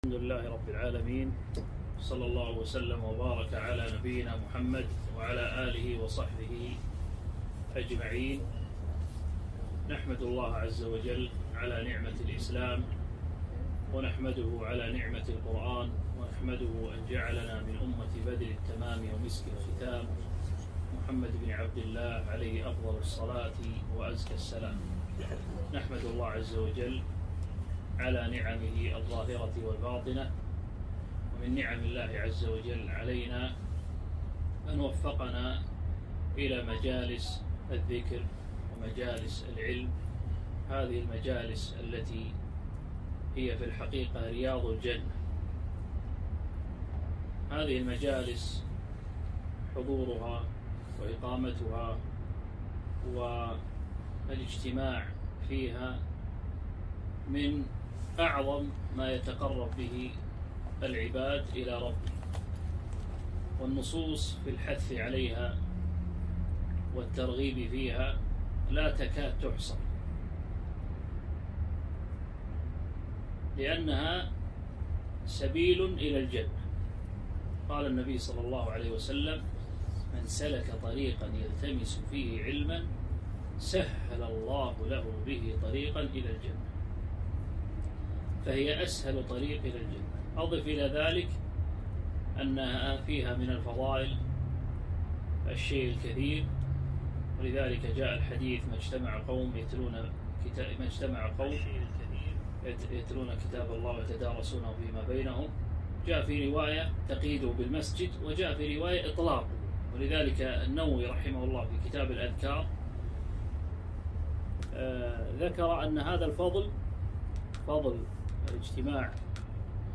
محاضرة - فوائد وعبر من قصة أصحاب الكهف